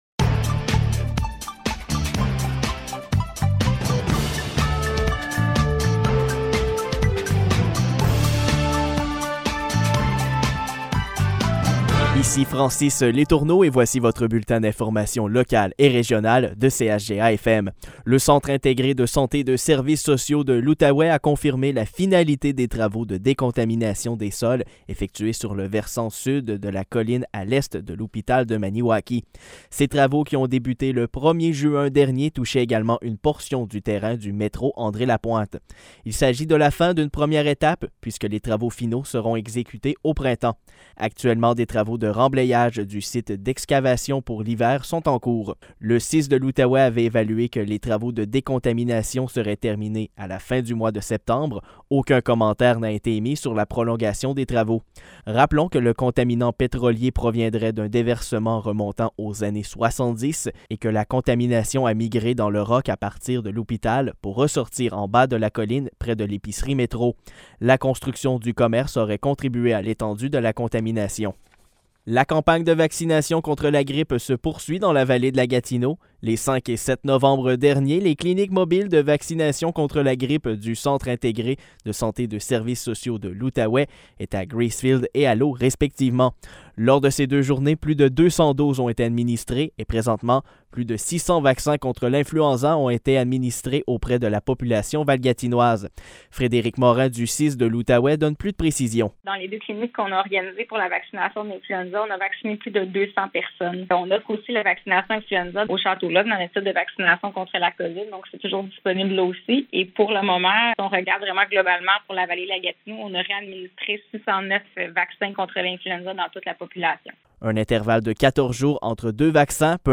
Nouvelles locales - 12 novembre 2021 - 12 h